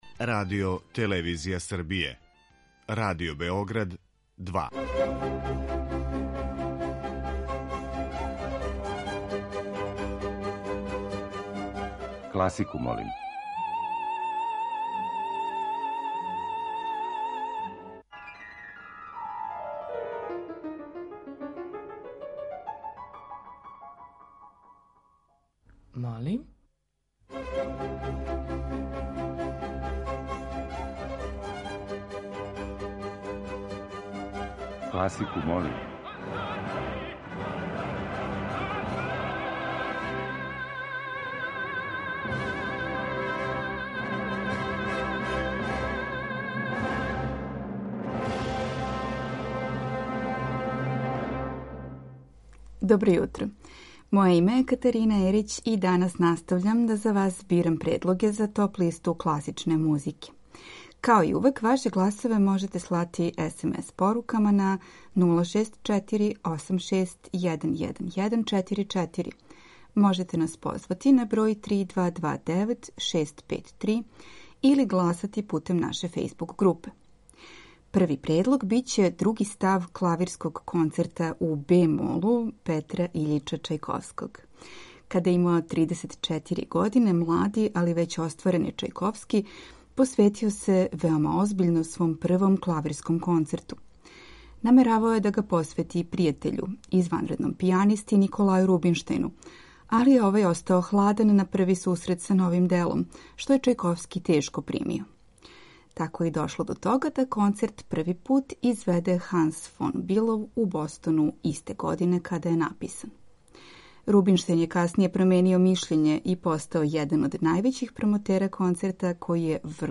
Емисија Класику, молим ове седмице нуди класичне, али и необичне спојеве инструмената.